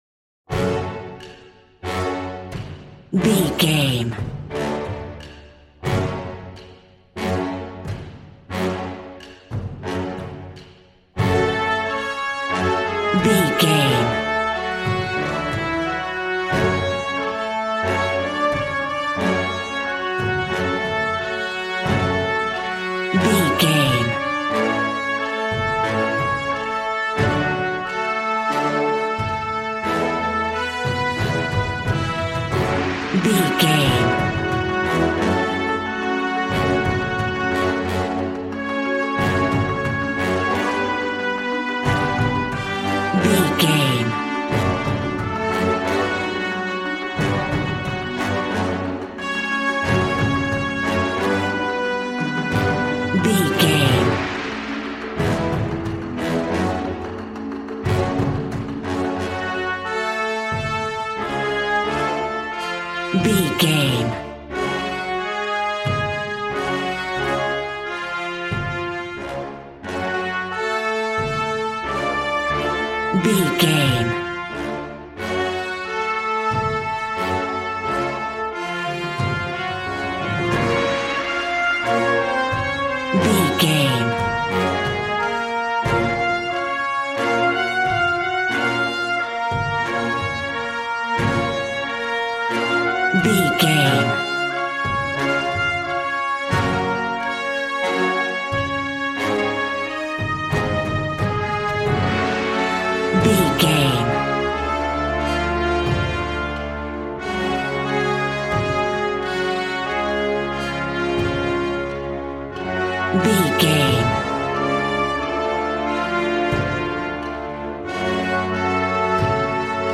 Regal and romantic, a classy piece of classical music.
Aeolian/Minor
G♭
regal
cello
double bass